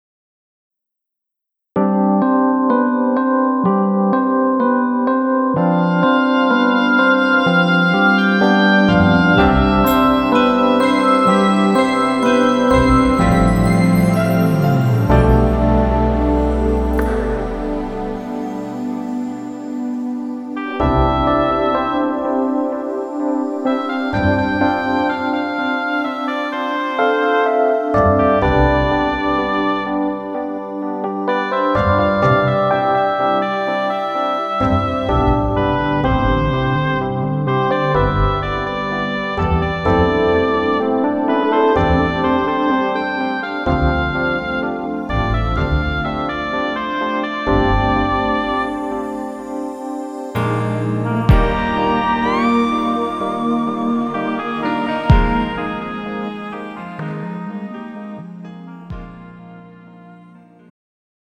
음정 멜로디MR
장르 축가 구분 Pro MR
가사   (1절 앞소절 -중간삭제- 2절 후렴연결 편집)